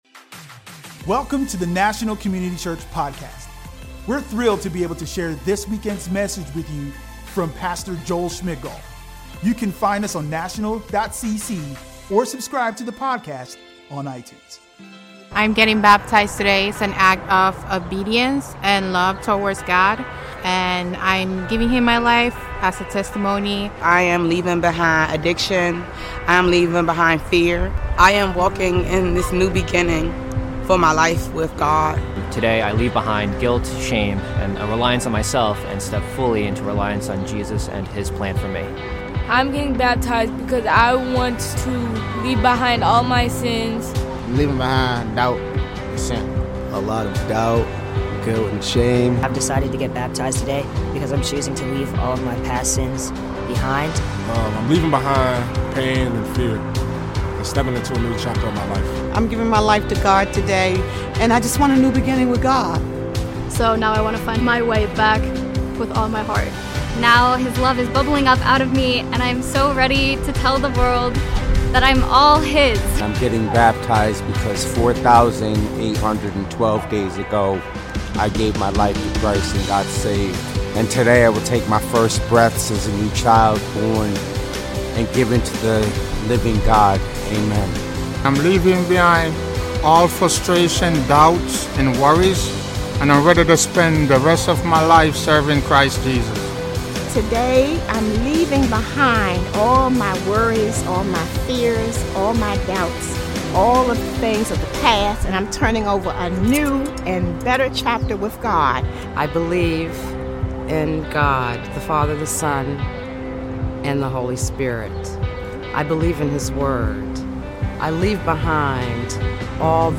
Audio messages from National Community Church in Washington, DC.